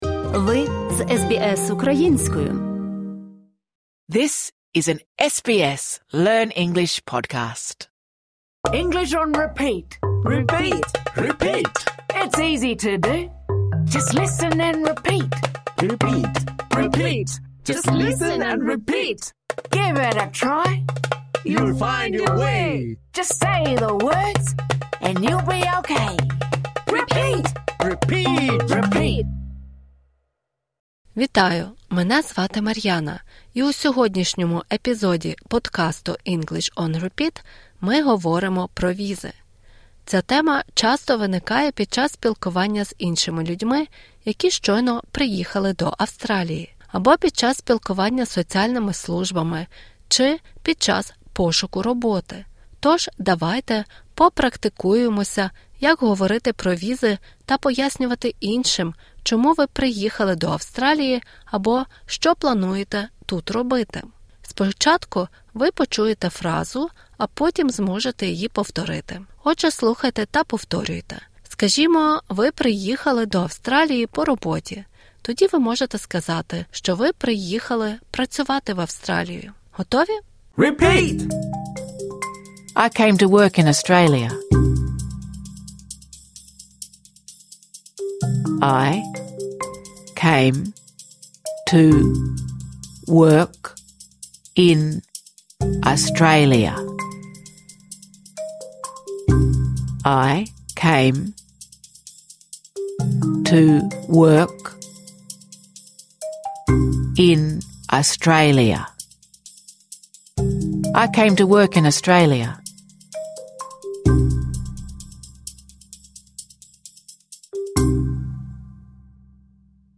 Цей урок призначений для початківців.